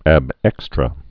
(ăb ĕkstrə)